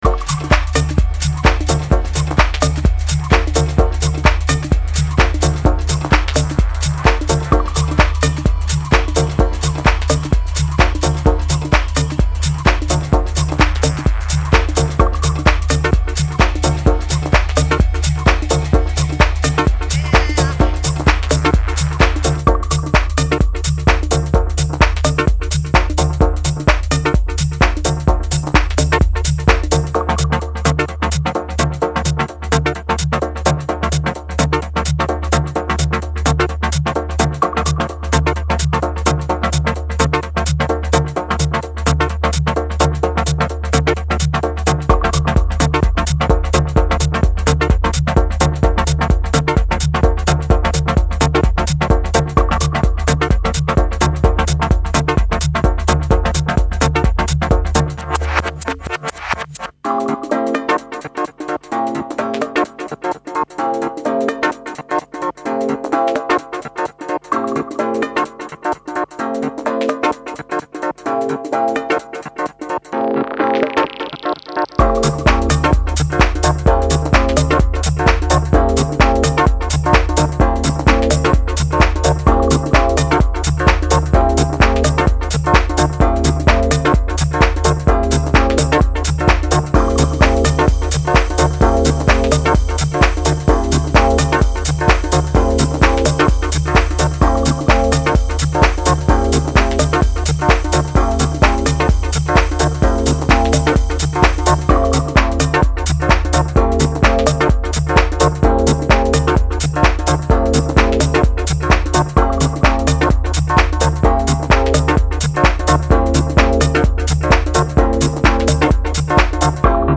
Muzyka/Music: House/Disco/Minimal Techno/Funk
Exclusive DJ mix session.